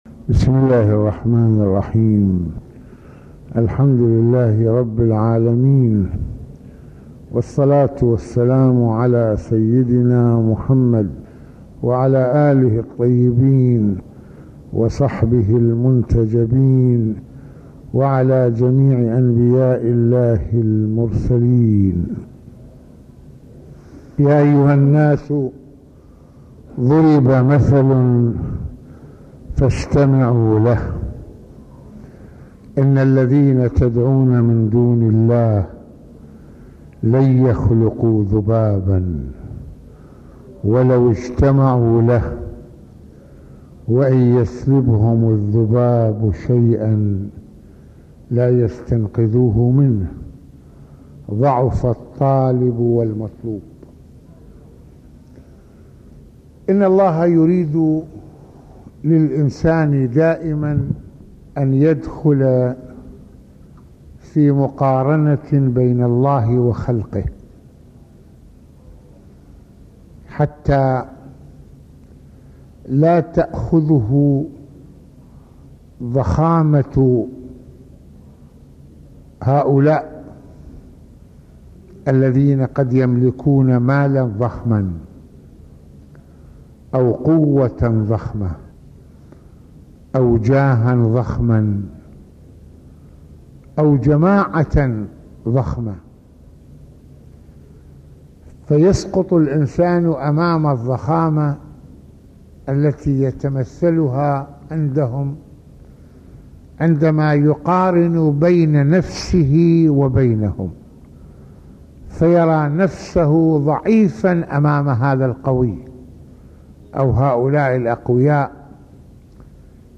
- يتحدث سماحة المرجع السيد محمد حسين فضل الله (رض) في هذه المحاضرة القرآنية عن أهمية معرفة قدر الله ويضرب بعض الأمثلة من الواقع من خلال معجزة الله في جعل الأسباب الطبيعية للخلق حتى لو كان بحجم الذبابة من هنا علينا الإقبال على تعظيم الله وعبادته والابتعاد عن طواغيت المال والجاه والأشخاص ، ويتعرض سماحته إلى منهج القرآن في التدليل على أهمية تربية عظمة الله في نفوسنا بما يؤكد حضورها وأصالتها ...